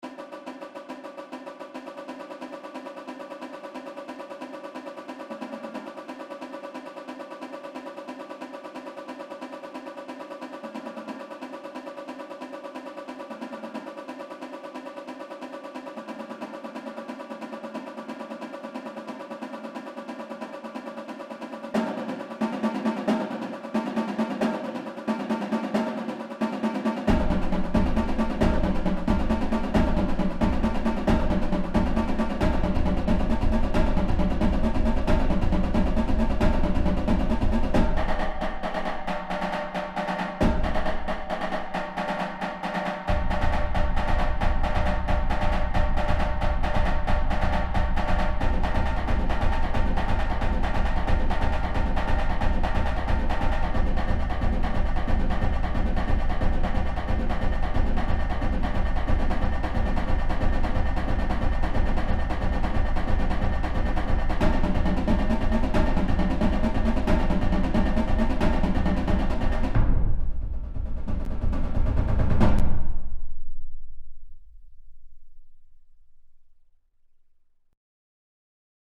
Multi-Percussion
Tom toms Bongo's Bass drum